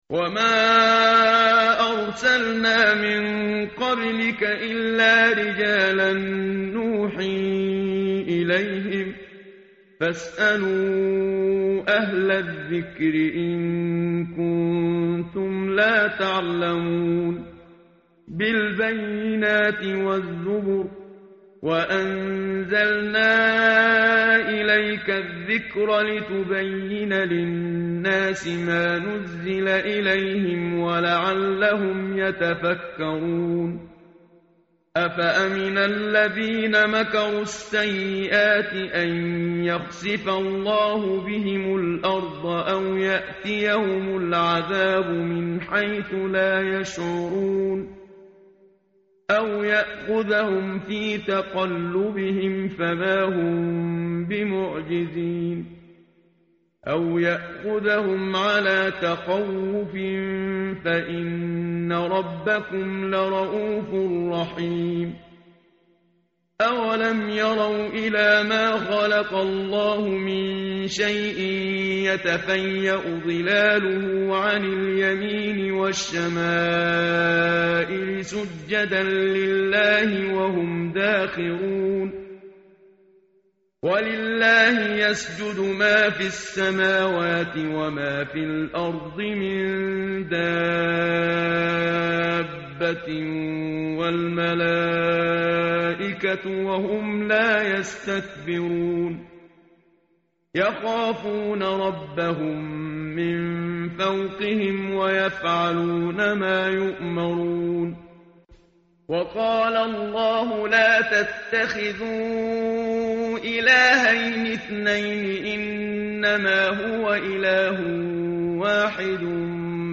tartil_menshavi_page_272.mp3